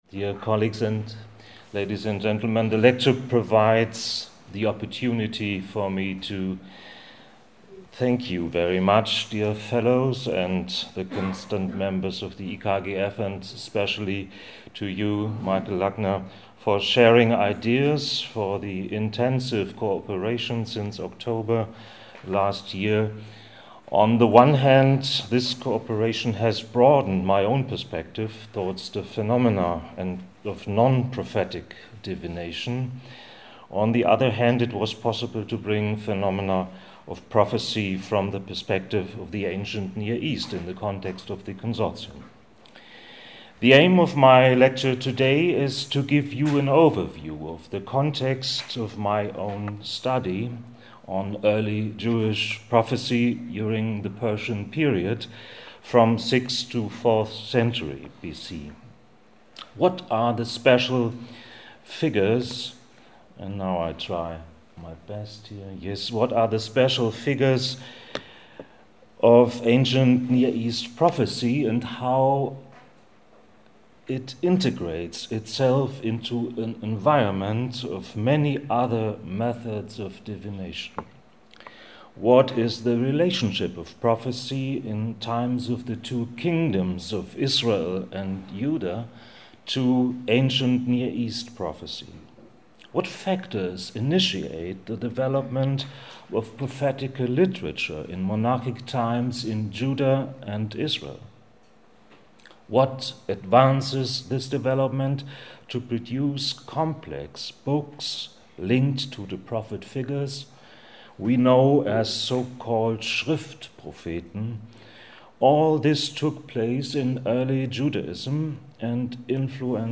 (PUBLIC SPEECH) Tibetische Bön-Religion und Divination Public Lecture